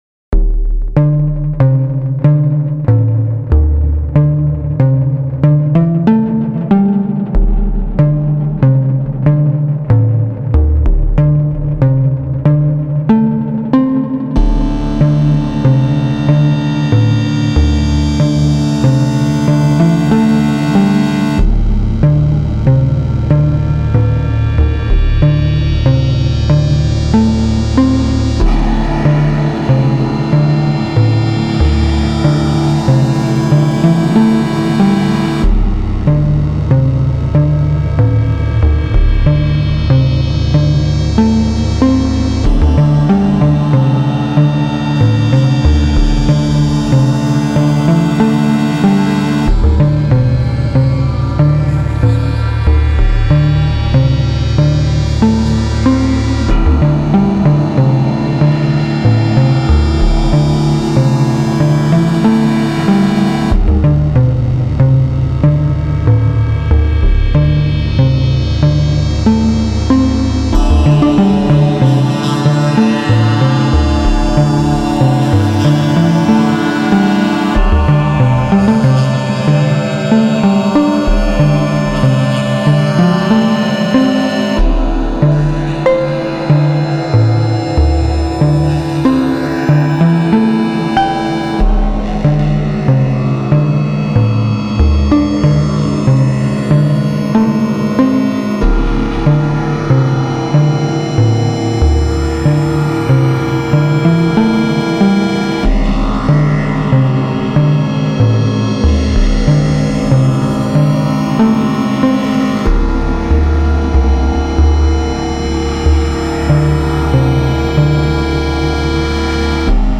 (MP3) (YouTube)   2:23 This one goes up to 11... 11/4 time, anyway.
Voice 1: 0-Coast, FM'd by Kermit, with the balance turned very close to full triangle. The bass on this thing is momentous.
Voice 2: ArcSyn, which is one of my favorite VST synths for sound design, with Valhalla Vintage Verb.
Voice 3: Rings in inharmonic string mode, through Sputnik QVCFA in VCA mode for once.